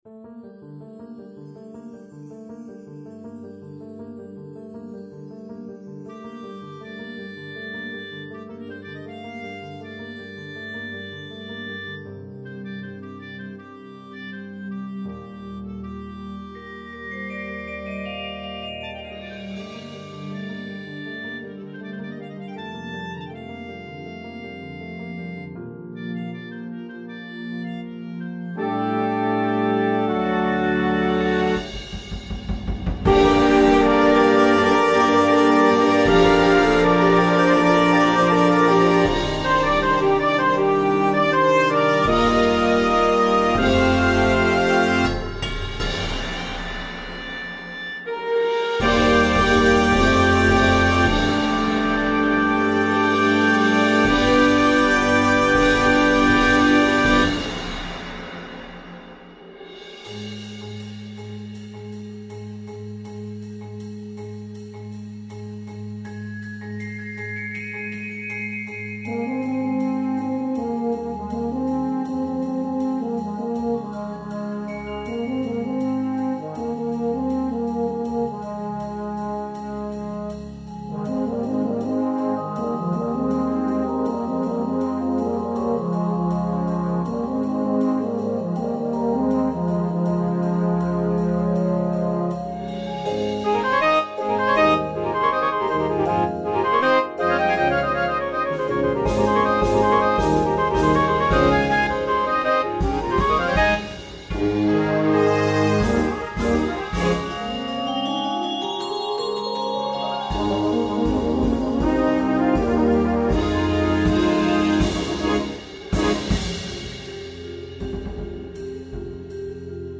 A show where the west meets sophistication.